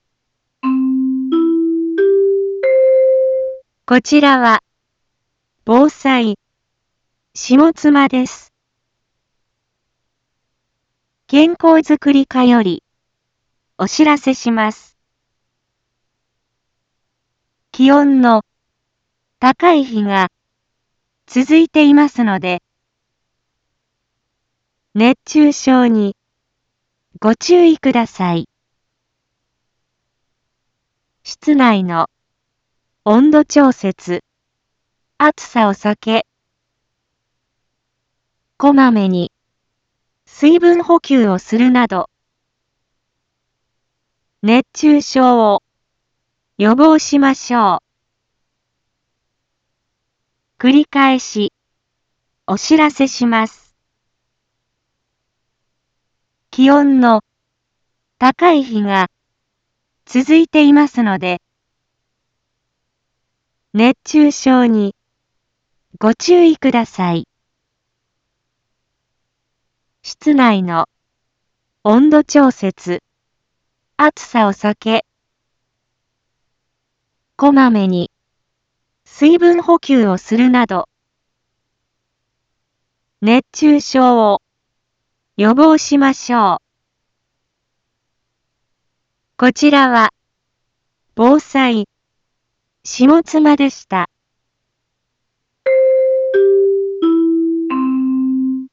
一般放送情報
Back Home 一般放送情報 音声放送 再生 一般放送情報 登録日時：2023-08-07 11:01:42 タイトル：熱中症注意のお知らせ インフォメーション：こちらは、防災、下妻です。